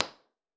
MIT_environmental_impulse_responses
Upload 16khz IR recordings
h014_HomeExerciseRoom_18txts.wav